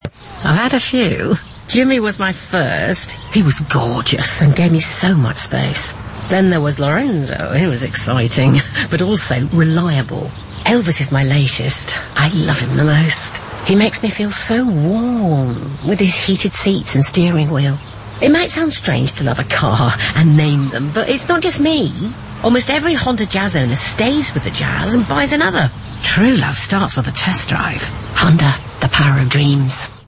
Only halfway through the 30-second spot do you realise we’re not eavesdropping in on a chat about the merits of Jimmy, Lorenzo and other ex-boyfriends.